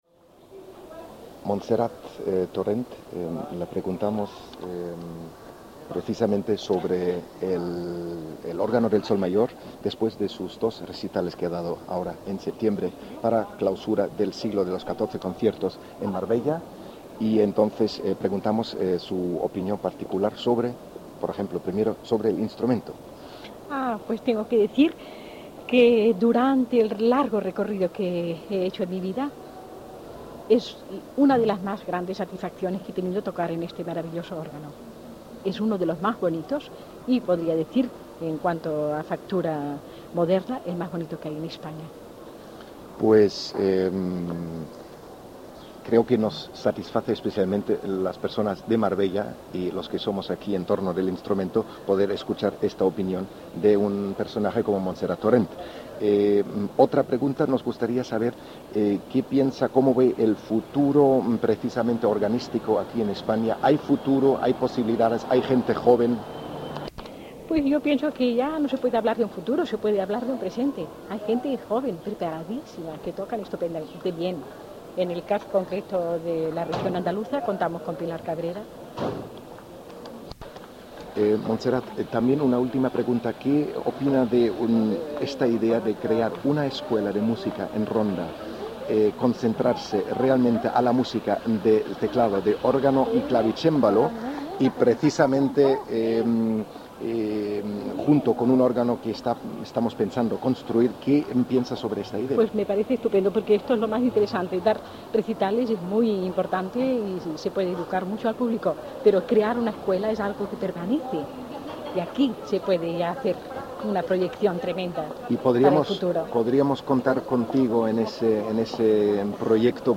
Entrevista con Montserrat Torrent después de su última actuación.
entrevista.mp3